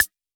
Index of /musicradar/retro-drum-machine-samples/Drums Hits/WEM Copicat
RDM_Copicat_MT40-Clave.wav